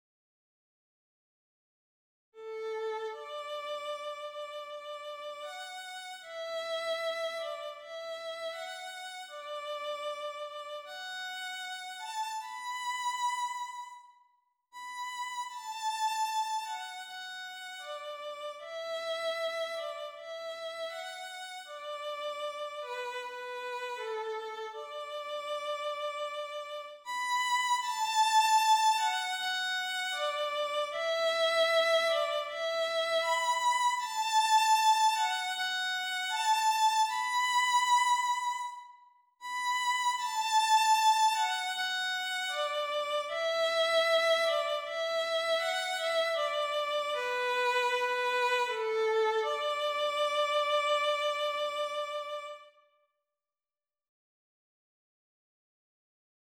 2권 Old Song -AULD LANG SYNE > 바이올린 | 신나요 오케스트라